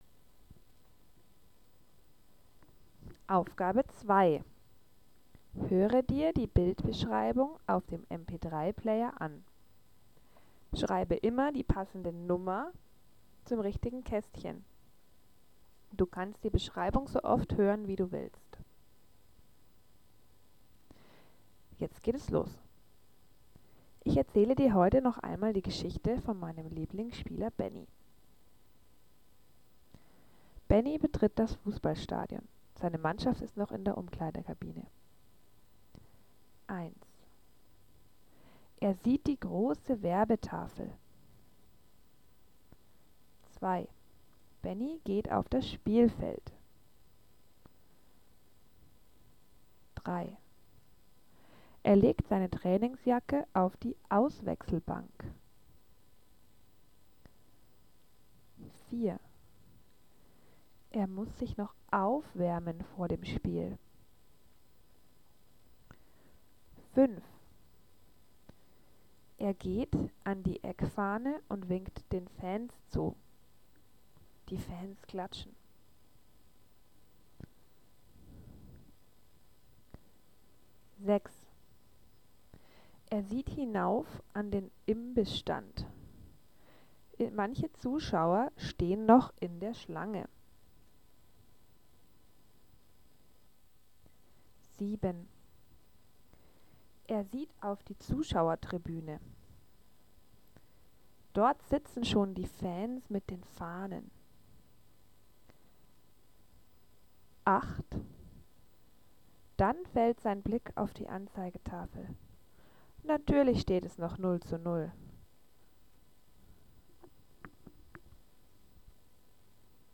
Art des Materials: Arbeitsblätter, Stationenarbeit, Spielideen, Unterrichtsmaterialien, Tafelbild, Hörspiel